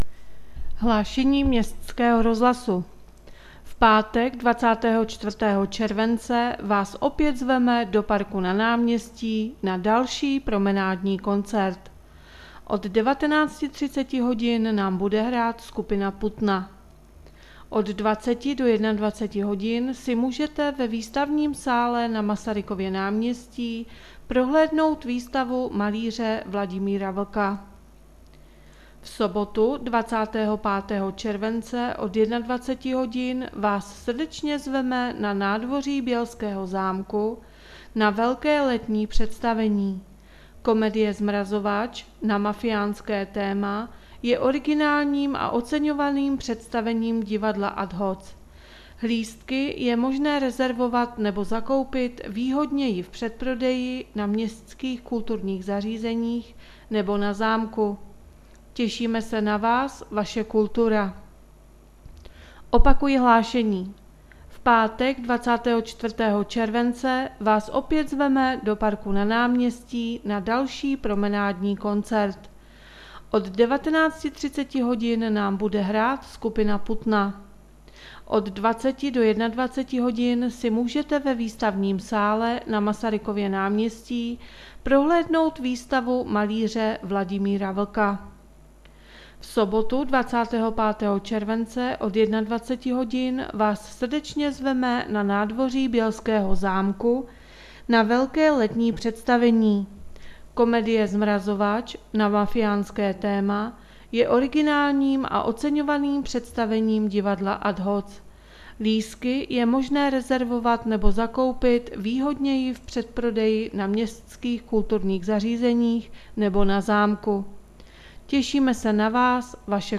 Hlášení městského rozhlasu 22.7.2020